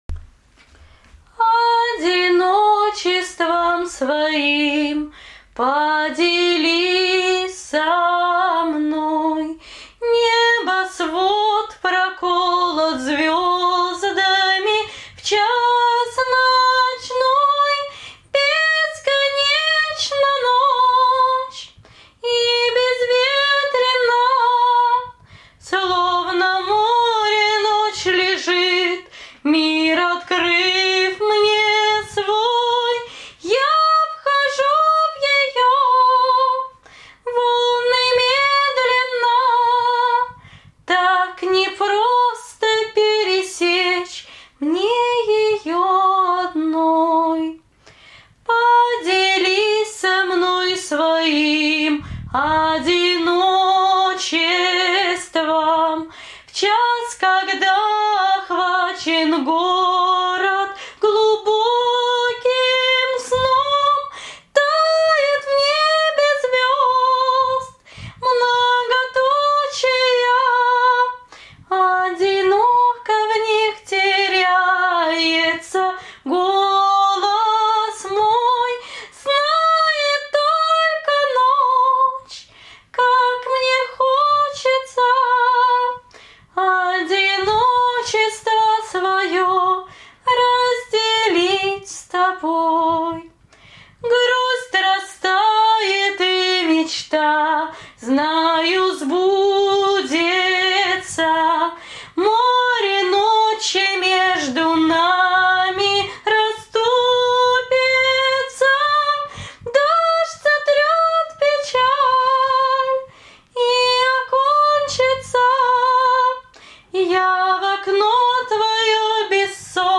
Акапельное пение